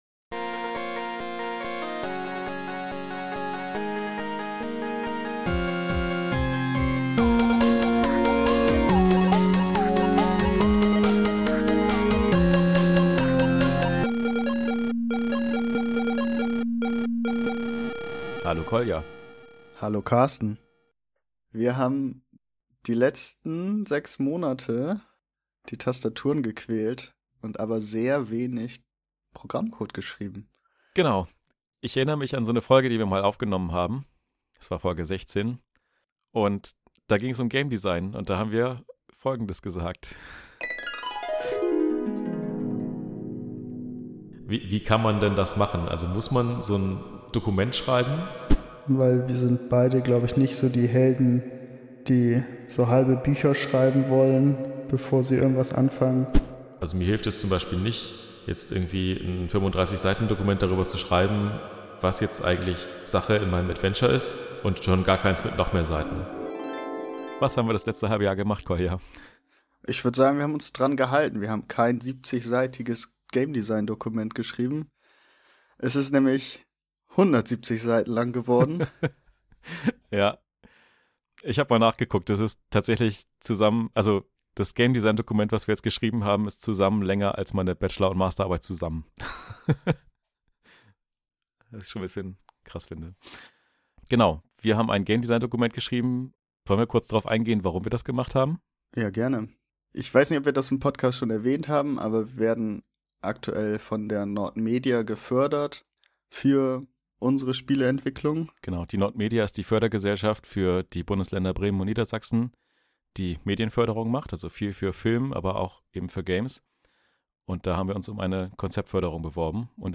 Zwei Softwareentwickler und Spiele-Enthusiasten entwickeln in ihrer Freizeit Videospiele und sprechen in einem Podcast über das, was sie tun, geben Tipps und diskutieren Technik sowie Methoden zur Spieleentwicklung.